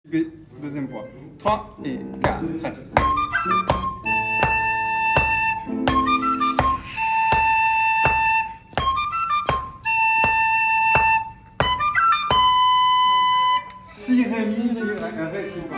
Les-boyaux-B-voix-2-diato.mp3